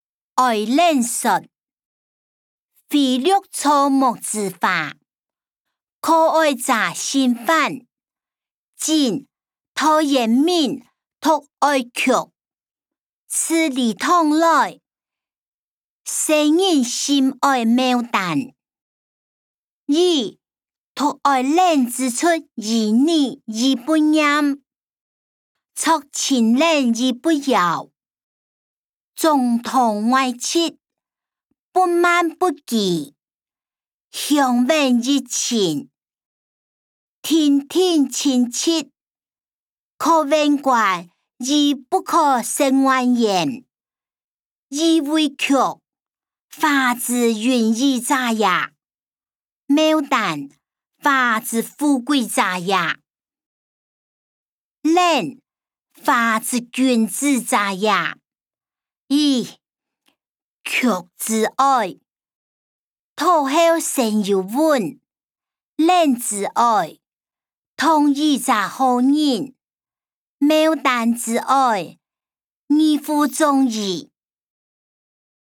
歷代散文-愛蓮說音檔(饒平腔)